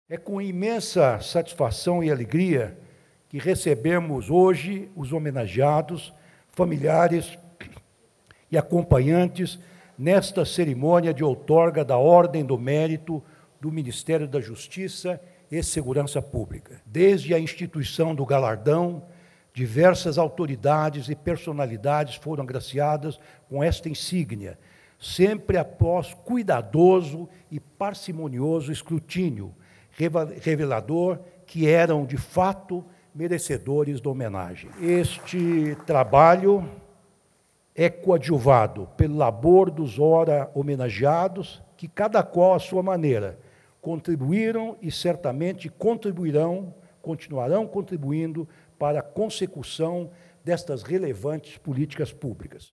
Ricardo Lewandowski, Ministro da Justiça, externa sua satisfação e fala sobre os agraciados — Ministério da Justiça e Segurança Pública